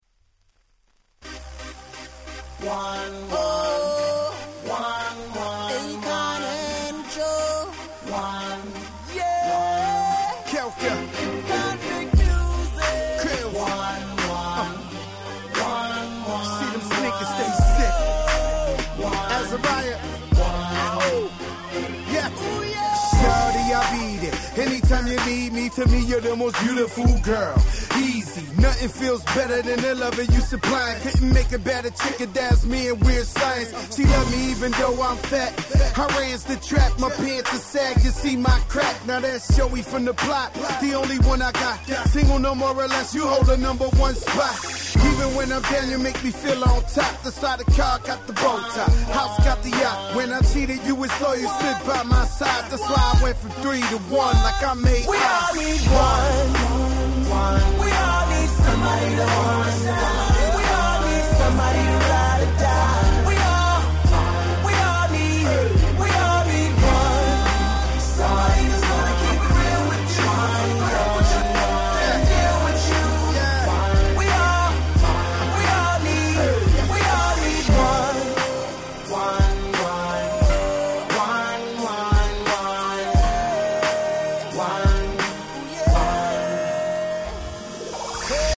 10' Nice Hip Hop !!